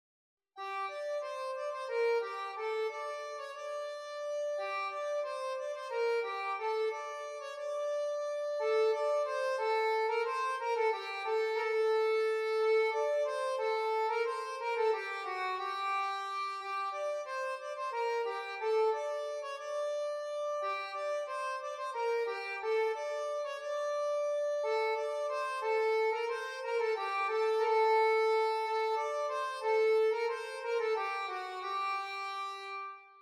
BOURRÉES D’AUVERGNE
valse-5.mp3